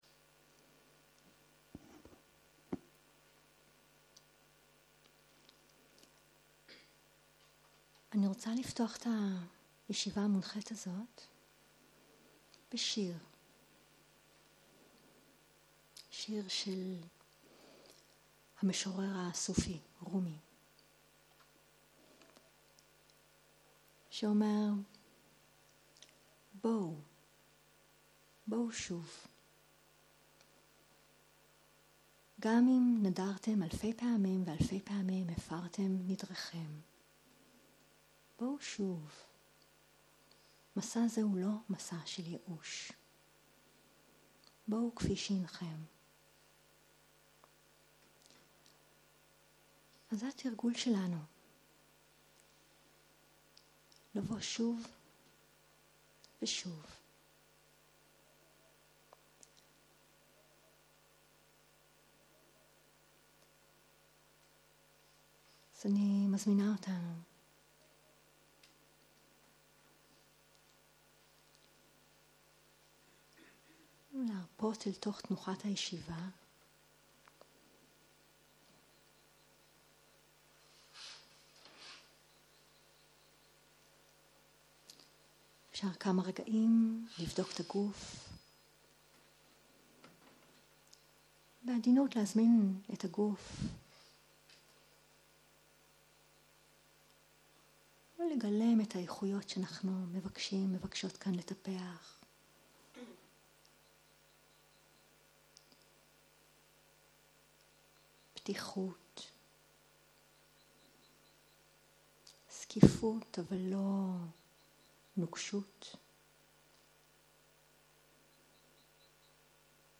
13.01.2023 - יום 2 - צהריים - מדיטציה מונחית - חזרה לנשימה - הקלטה 3